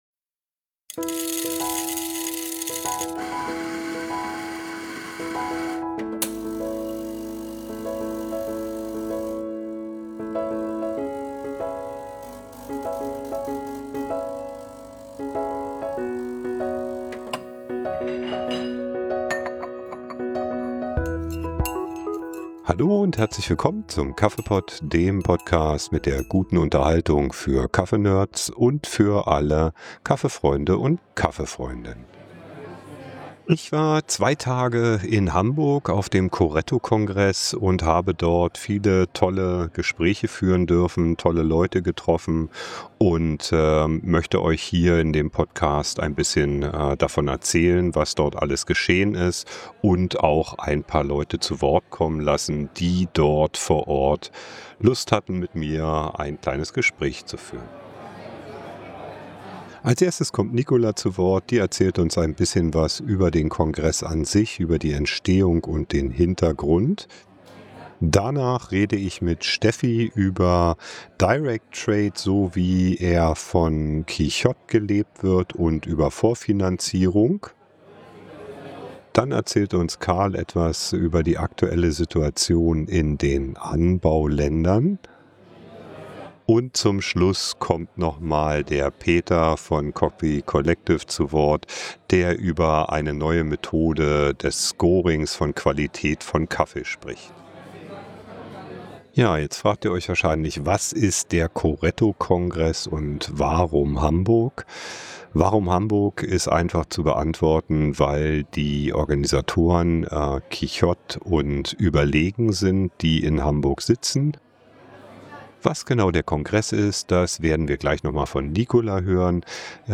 In dieser Episode des KaffeePod nehme ich die Zuhörer mit zum Coretto-Kongress in Hamburg.